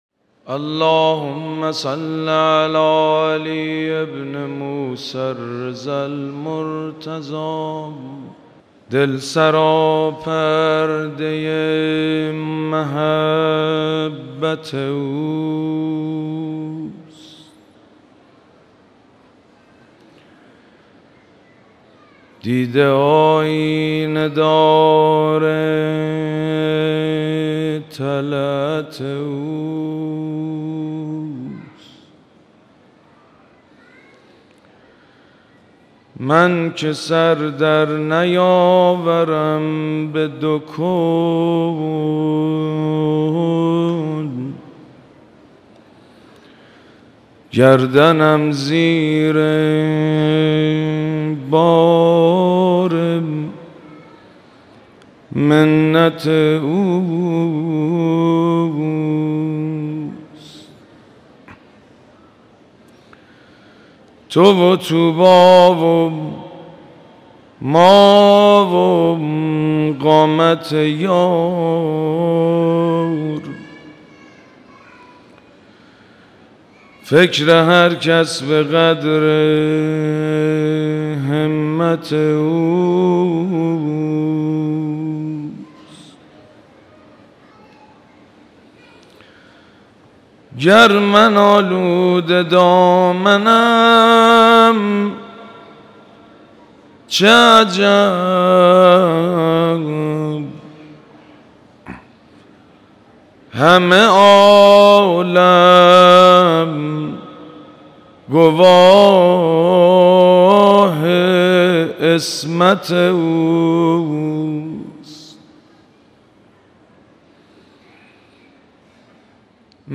مراسم عزاداری ظهر سی‌ام ماه صفر
حسینیه امام خمینی (ره)
شعر خوانی